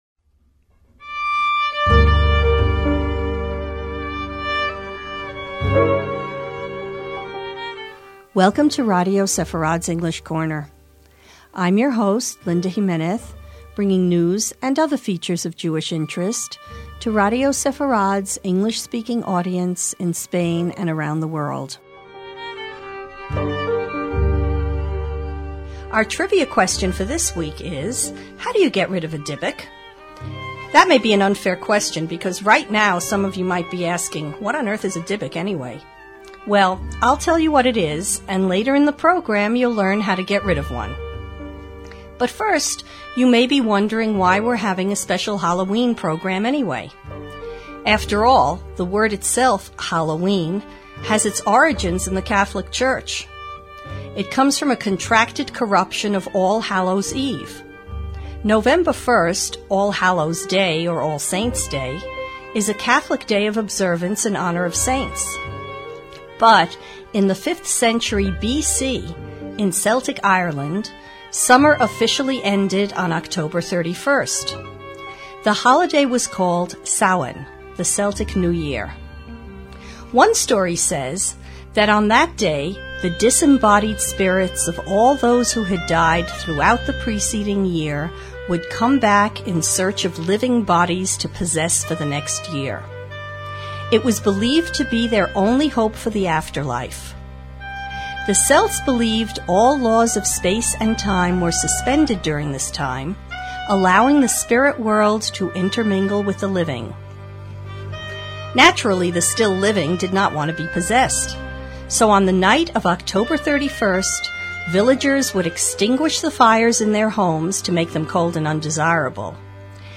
As a Halloween treat, the English Corner is presenting a reading of scenes from Anski’s play , which we originally recorded in 2005.